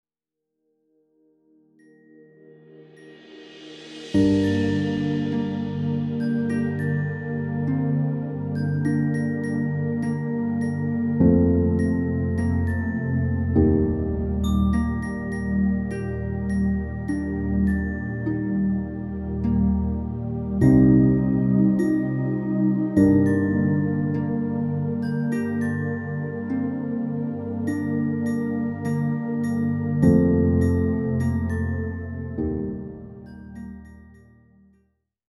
Lullaby covers